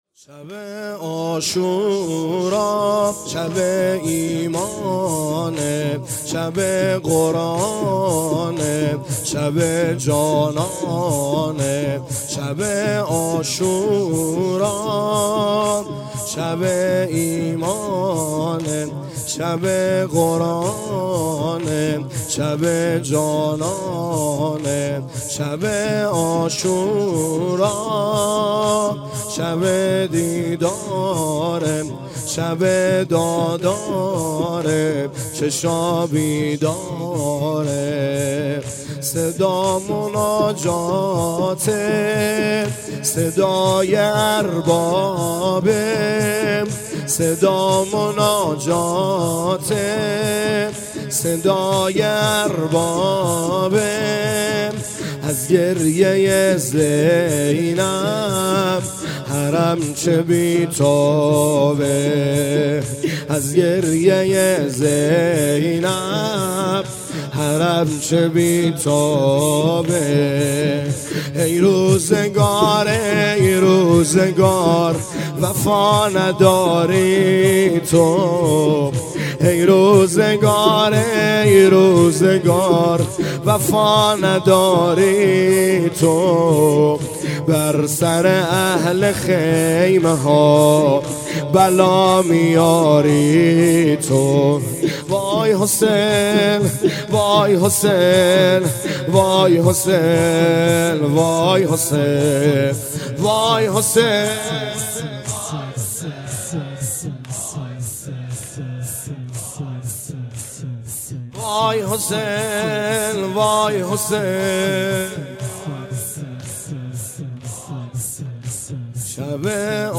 زمینه | شب عاشورا شب ایمانه
گزارش صوتی شب دهم (عاشورا) محرم 97 | هیأت محبان حضرت زهرا سلام الله علیها زاهدان